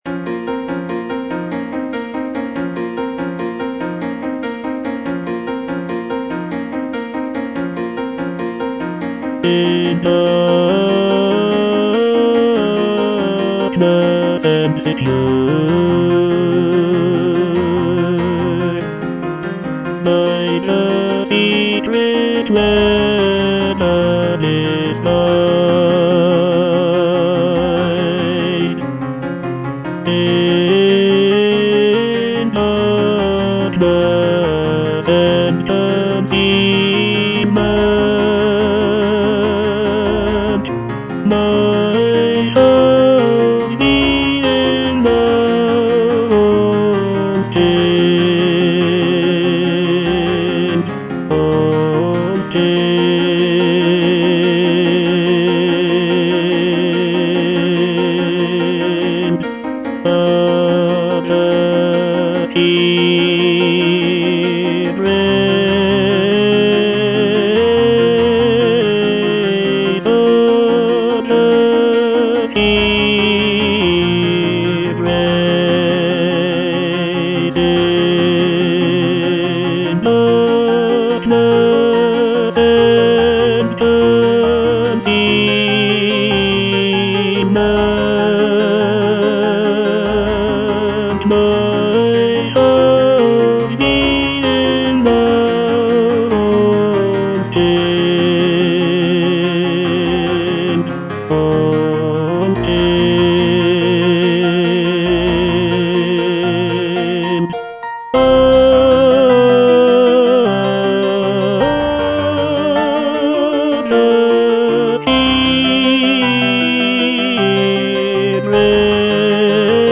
tenor I) (Voice with metronome) Ads stop
a choral work